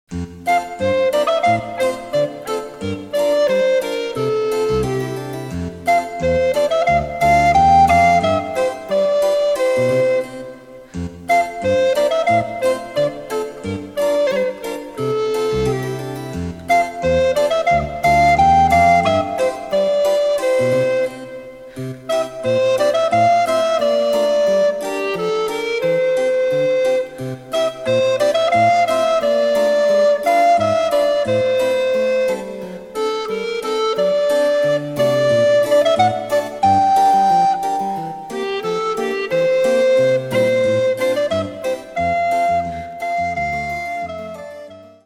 デジタルサンプリング音源使用
・伴奏はモダンピッチのみ。